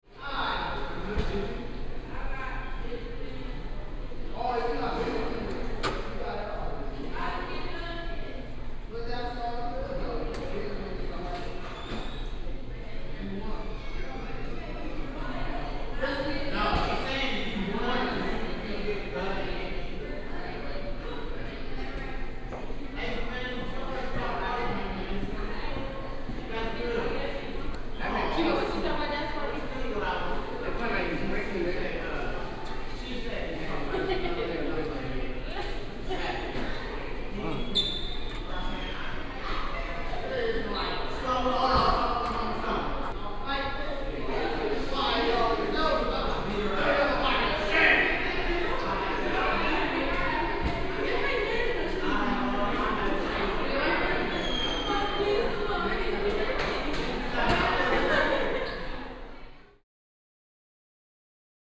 ambience
High School Gymnasium Ambience